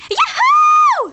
One of Princess Daisy's voice clips in Mario Kart: Double Dash!!